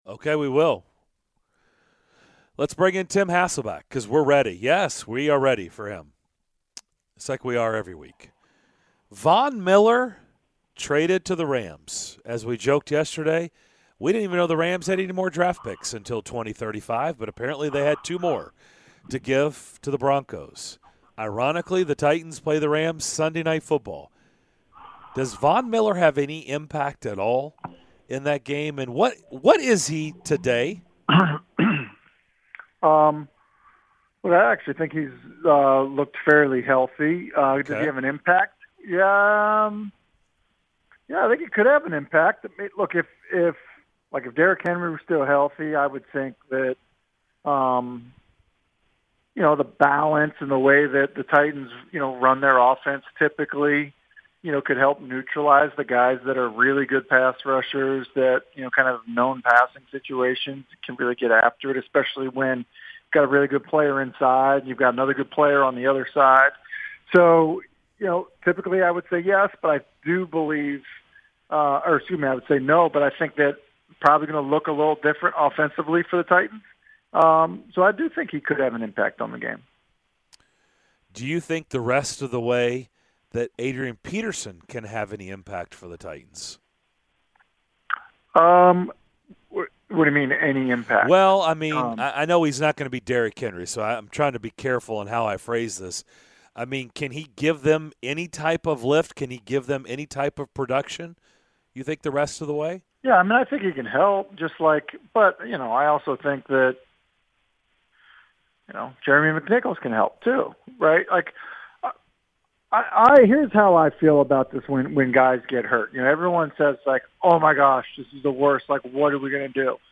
ESPN's Tim Hasselbeck joined the DDC to give his thoughts on the latest from around a busy week in the NFL!